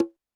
Drums_K4(26).wav